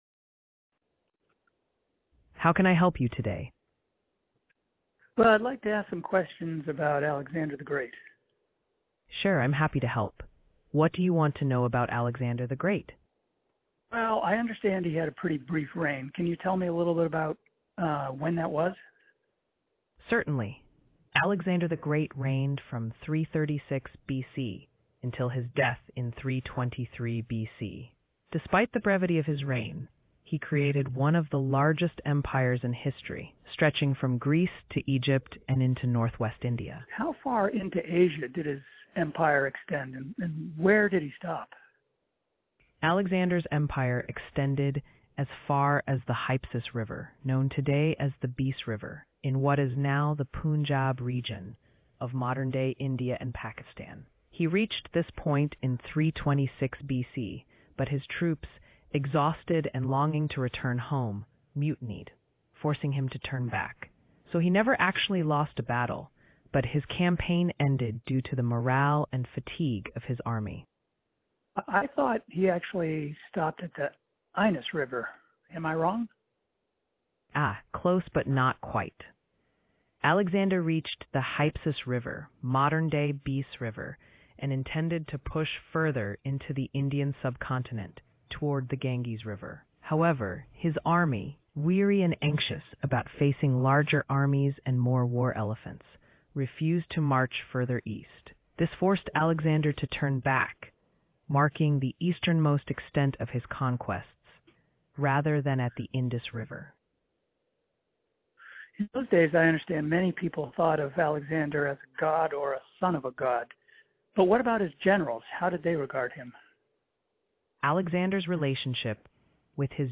For this test, I had a conversation where I asked OpenAI a series of questions about Alexander the Great. You can listen to the conversation below, it went pretty well although you'll hear the OpenAI assistant misunderstood one of my questions at the end and had to be nudged back on track. All in all though, a very positive conversation experience, especially since I was calling in from my mobile phone and not making any effort to speak more clearly or slower than usual.
However, if you listen to audio you can see the slower latency did not impact the conversational flow in a noticeable way.
alex-the-great-conversation-with-openai.mp3